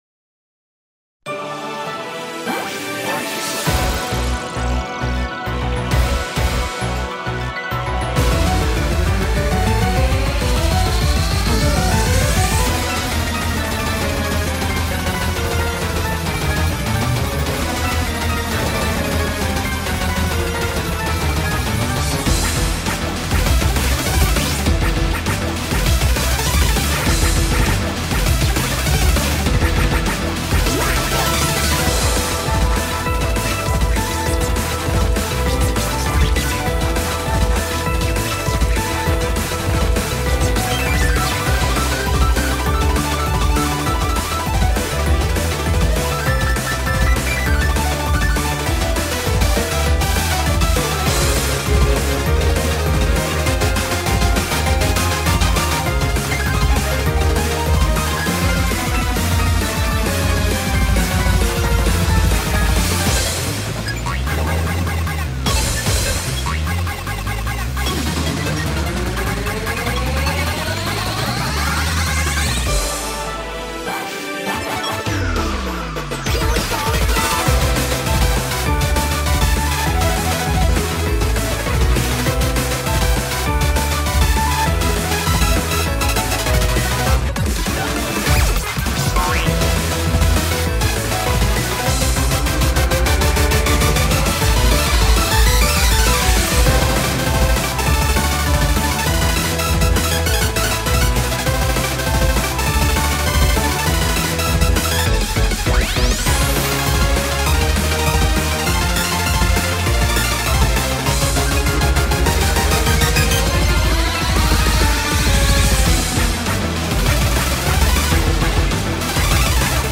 BPM200
Audio QualityCut From Video